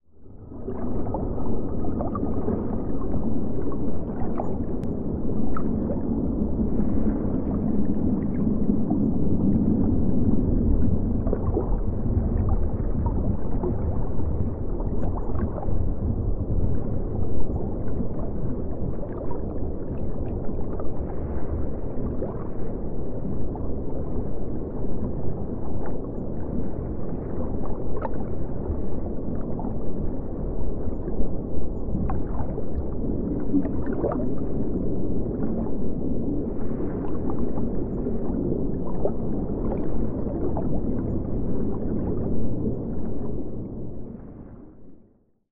Tiếng lặn, bơi dưới nước, sông, biển
Thể loại: Hiệu ứng âm thanh
Description: Tiếng người bơi lặn, bơi dưới nước, sông, biển, tiếng bong bóng nước ở dưới nước, tiếng hơi thở của con người dưới nước sâu, trong lúc bơi, lặn dưới nước, dưới biển thở ra bong bóng khí sủi bọt...
tieng-lan-boi-duoi-nuoc-song-bien-www_tiengdong_com.mp3